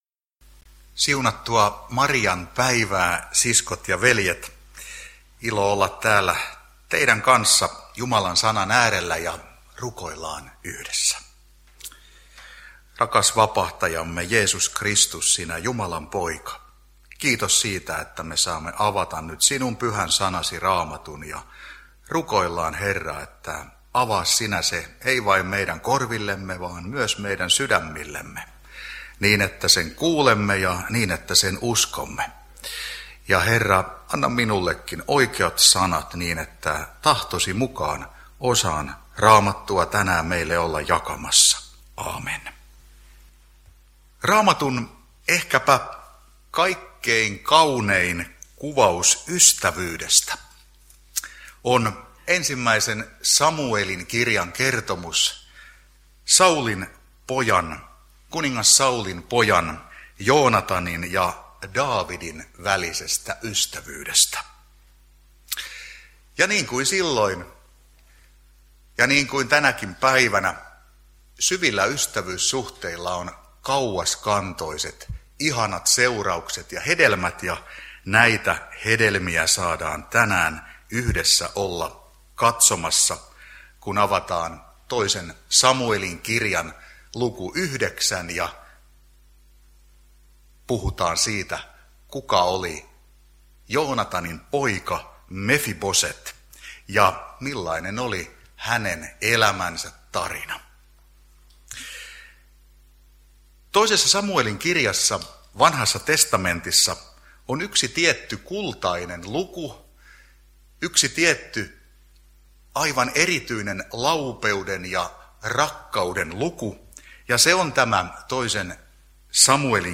Niinisalossa marianpäivänä Pohjana 2. Sam. 9